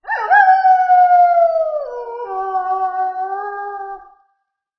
boss_lang_jiao.wav